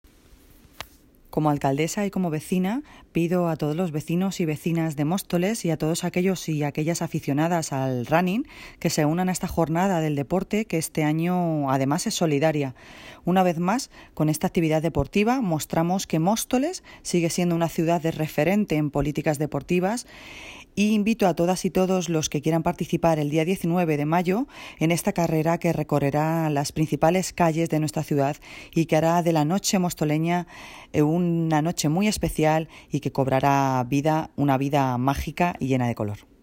Audio - Noelia Posse (Alcaldesa de Móstoles) Sobre Ilumina Móstoles